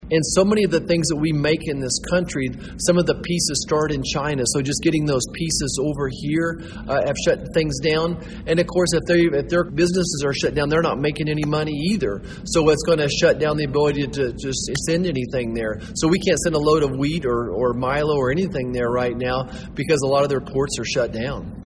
Touting a message of optimism for Kansas farmers, 1st District Congressman and current Republican Senate candidate Roger Marshall spoke to a gathering at the annual Young Farmers & Ranchers Leaders Conference Saturday at the Manhattan Conference Center.